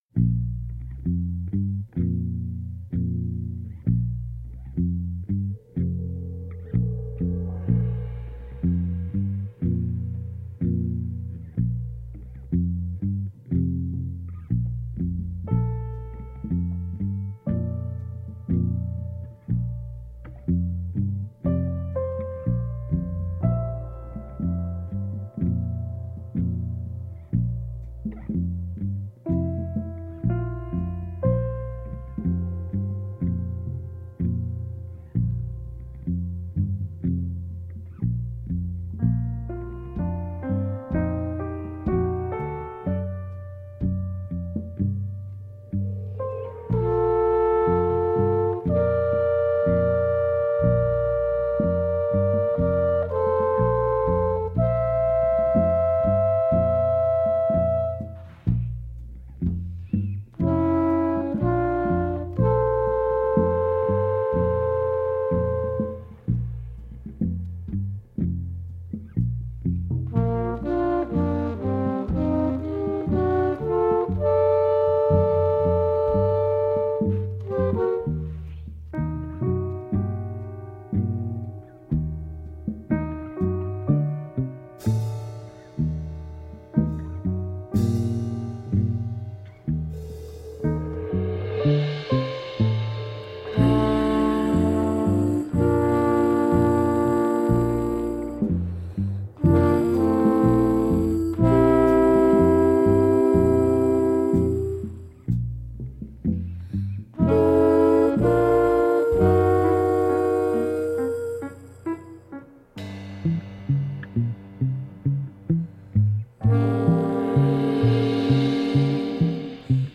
Lively sardinian/italian world music sextet from rome.
clarinet. Sung in English.
Tagged as: Jazz, Folk, World Influenced